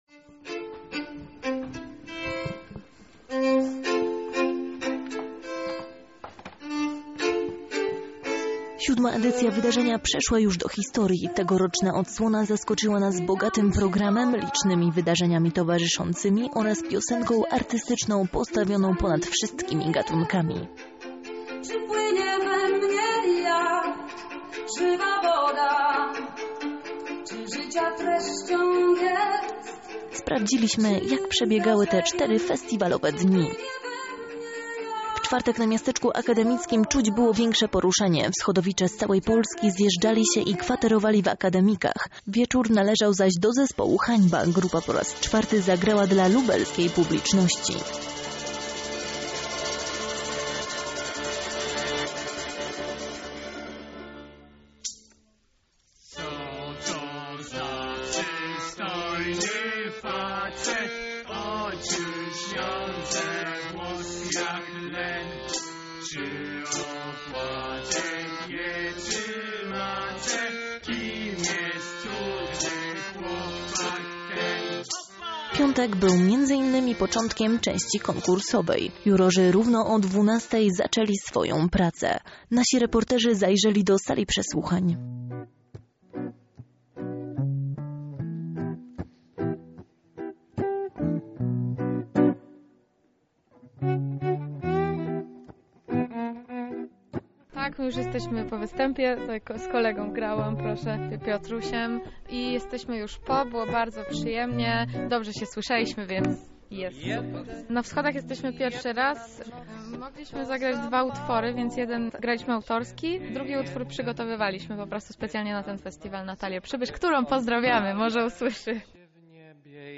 • Nasze reporterki przygotowały podsumowanie tego wydarzenia